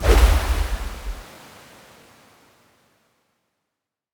water sword Buff 1.wav